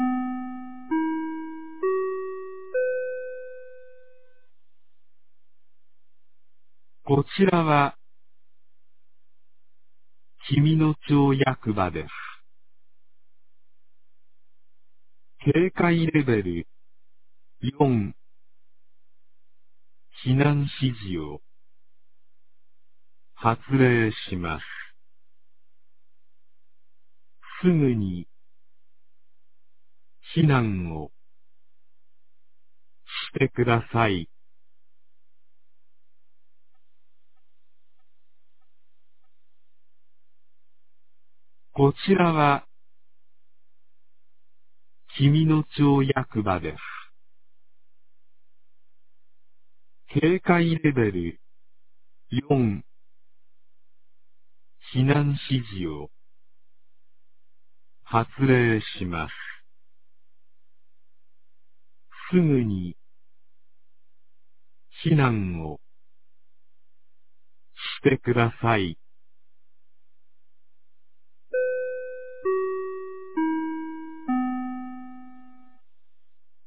2023年06月02日 13時12分に、紀美野町より東野上地区へ放送がありました。
放送音声